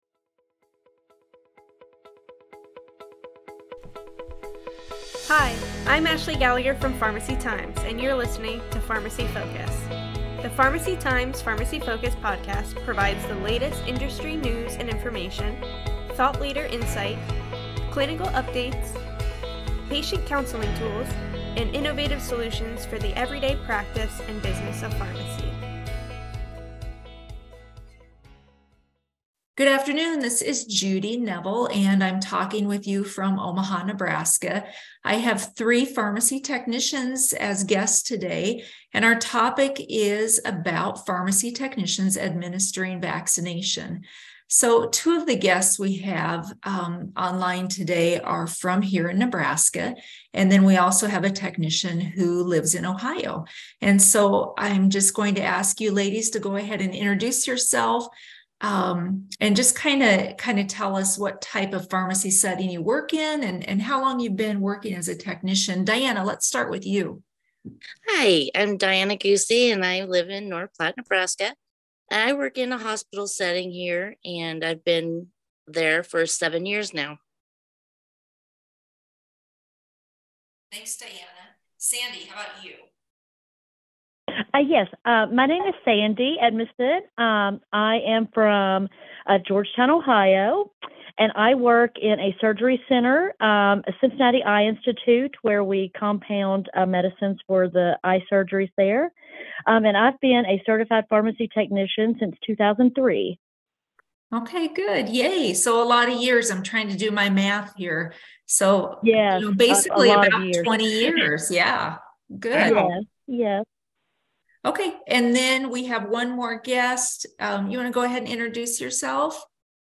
speaks with pharmacy technicians about the impact of pharmacy technician as vaccinators.